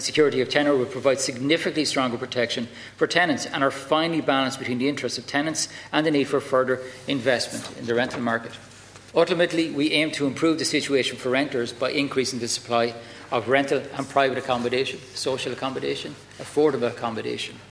Housing Minister James Browne had told the Dail the government’s intention is to see a significant increase in the supply of rental accomodation.…………….